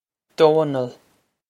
Domhnall Doh-nul
Pronunciation for how to say
This is an approximate phonetic pronunciation of the phrase.